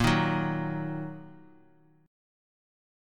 A#sus4#5 chord